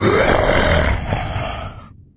troll_warrior_attack.wav